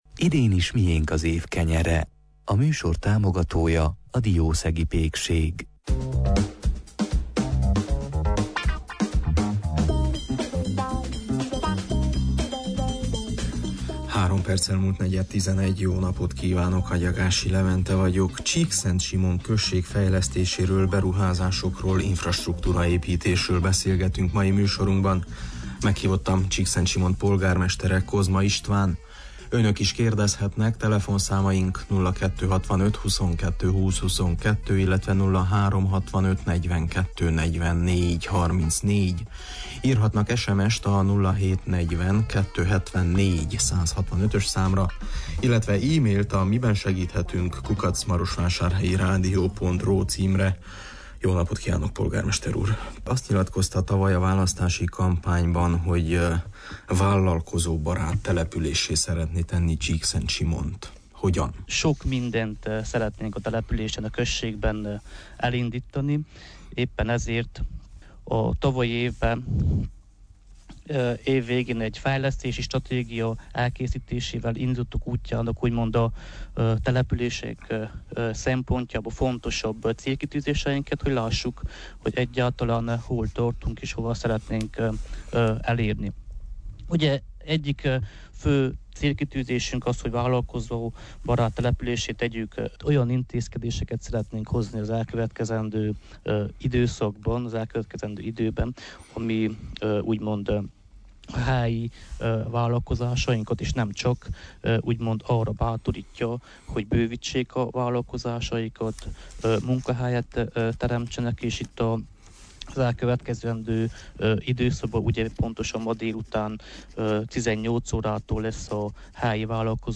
Vállalkozóbarát községgé szeretné alakítani Csíkszentsimont a község polgármestere. Egy fejlesztési stratégiát dolgoztak ki, amely alapján elkezdődhet a község ez irányú fejlesztése. Keddi műsorunkban Csíkszentsimon első embere, Kozma István infrastrukturális beruházásokról, visszaszolgáltatásokról is beszélt: